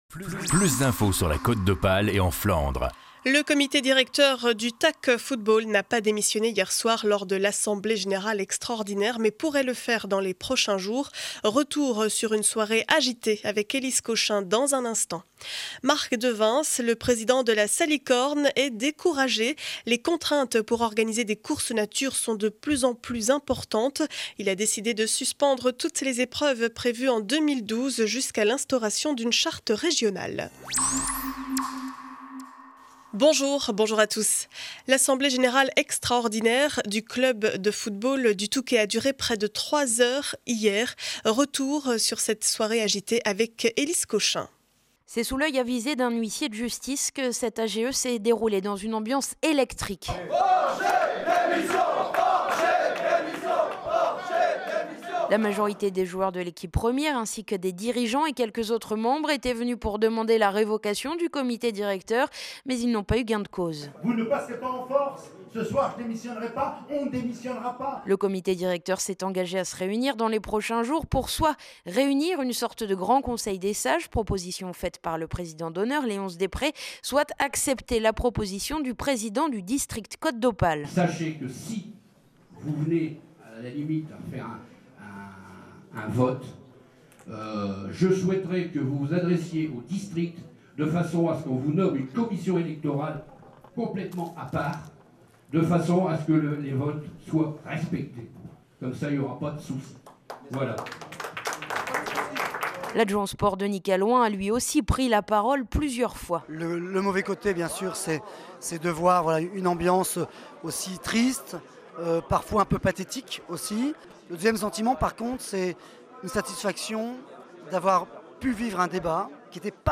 Reportage AGE TAC football